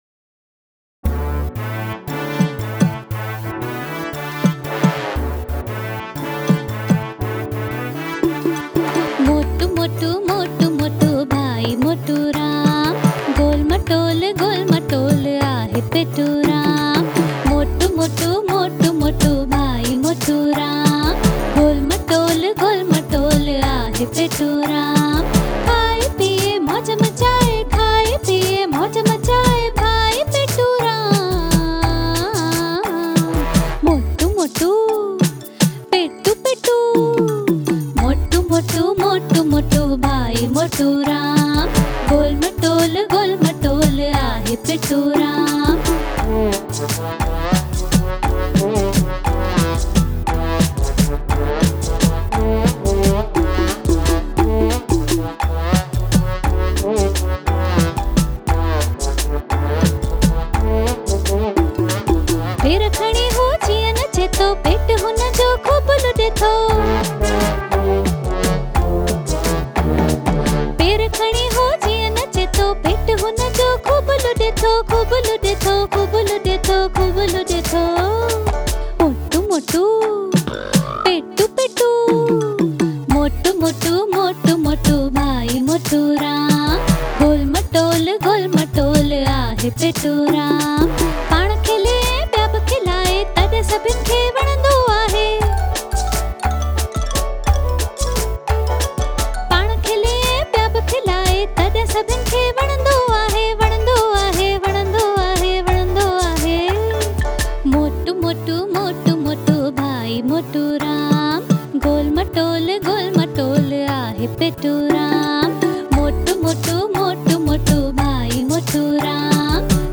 Sindhi Nursary Rhymes